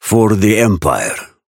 Raven voice line - For the Empire.